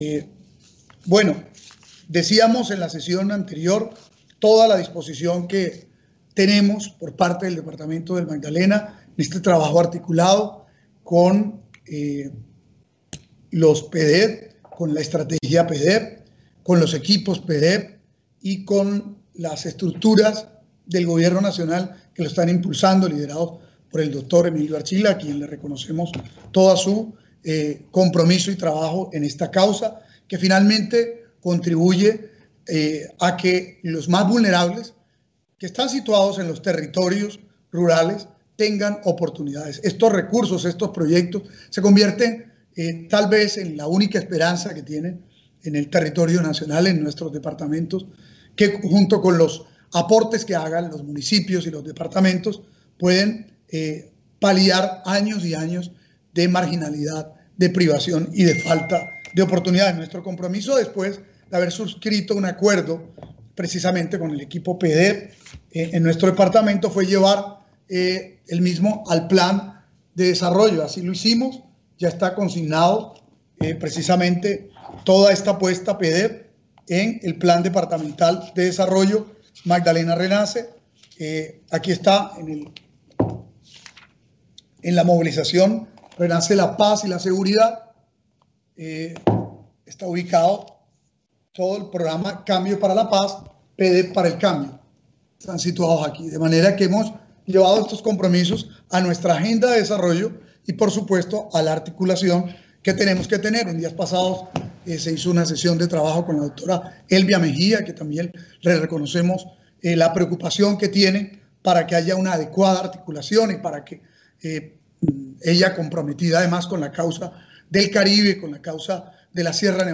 Precisamente, así lo destacó el Consejero Presidencial para la Estabilización y Consolidación, Emilio José Archila Peñalosa, durante la intervención de Caicedo en la reunión virtual de seguimiento al cumplimiento del PDET Sierra Nevada- Perijá, en el cual también vienen avanzando La Guajira y Cesar.
PALABRAS-DEL-GOBERNADOR-EN-EL-PDET.mp3